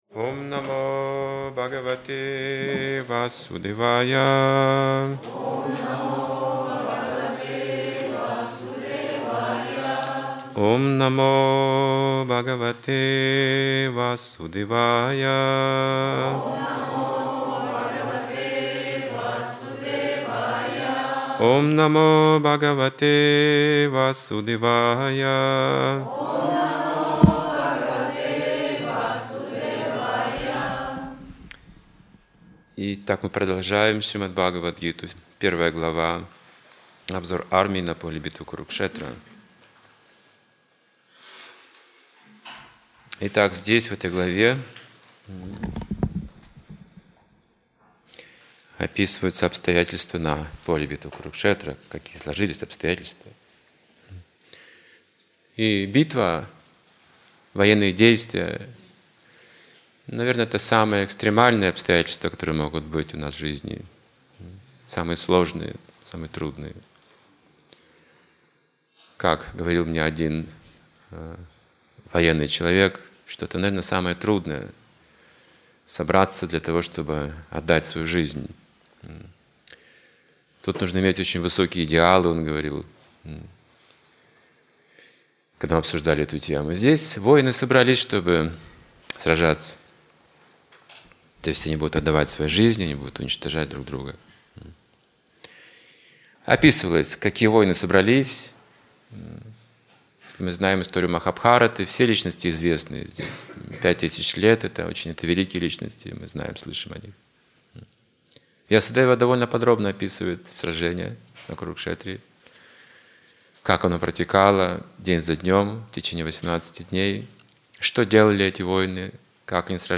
Темы, затронутые в этой лекции: Сострадание: качество чистого преданного или слабость?